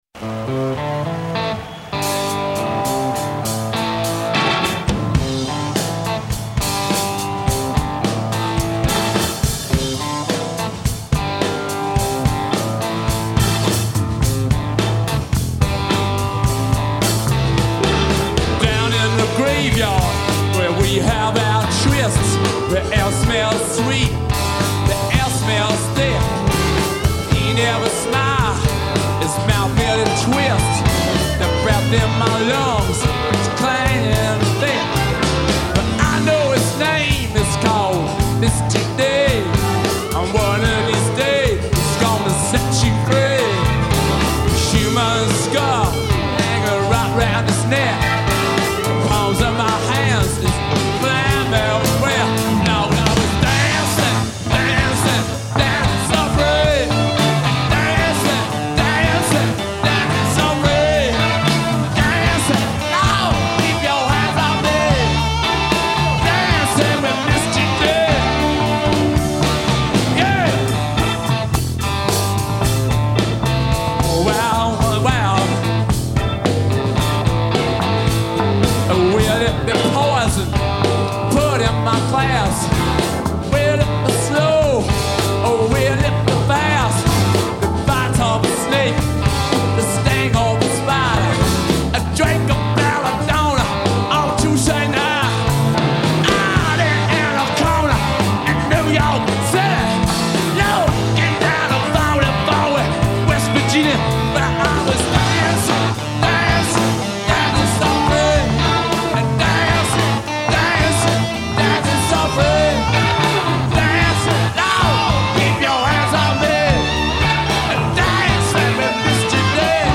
lead guitar
Amazing sound.